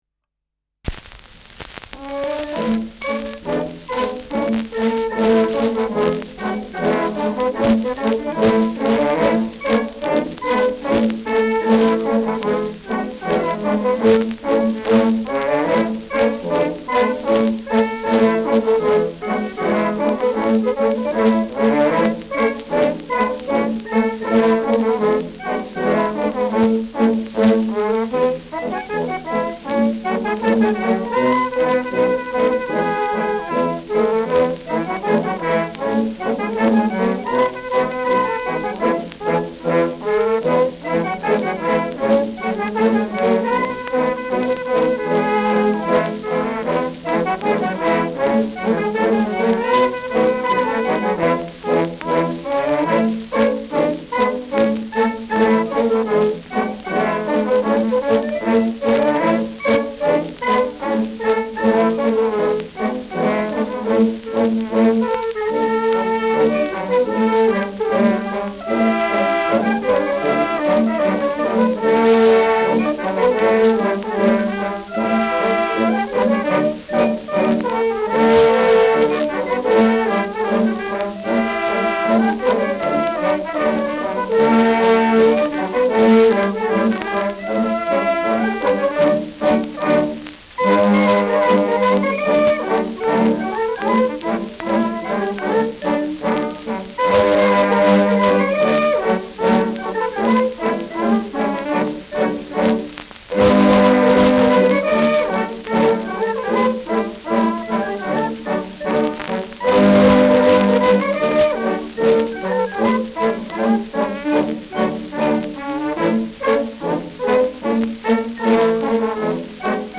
Mädel wasch dich : Rheinländer
Schellackplatte
leichtes Rauschen
Niedersächsische Bauernkapelle (Interpretation)